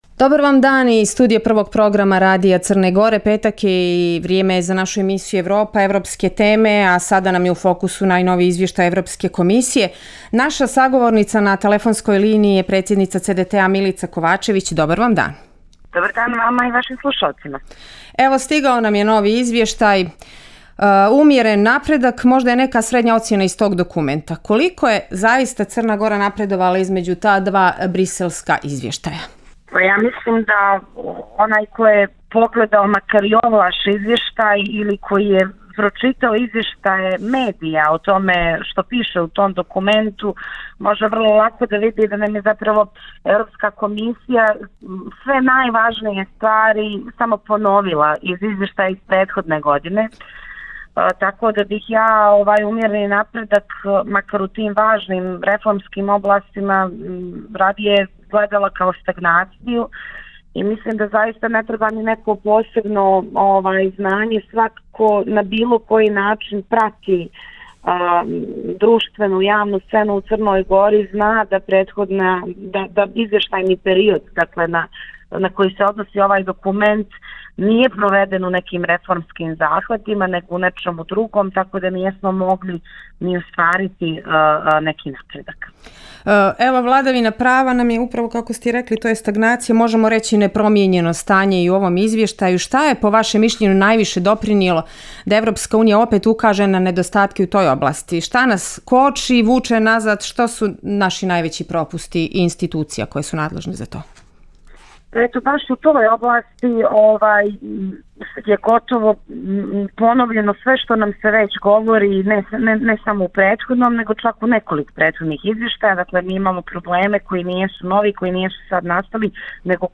ocjene iz posljednjeg Izvještaja Evropske komisije o Crnoj Gori na Radiju Crne Gore.